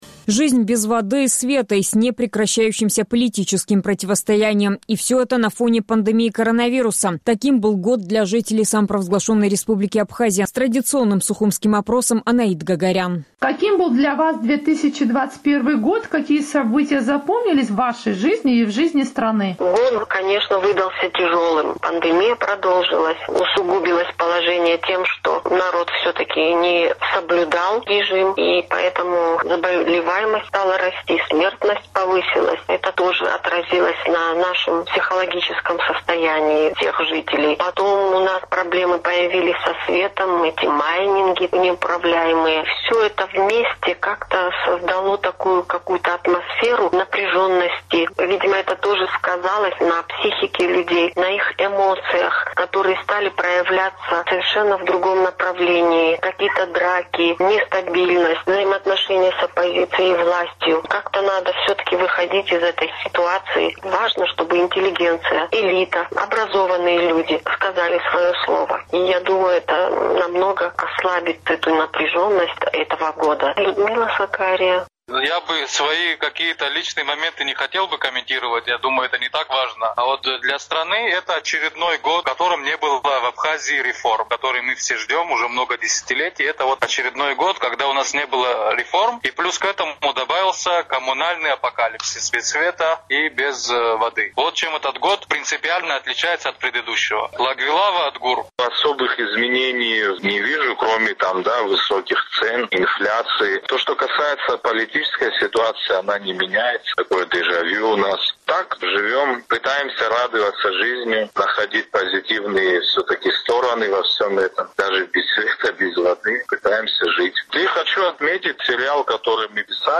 Таким был год для жителей Абхазии. Наш традиционный сухумский опрос.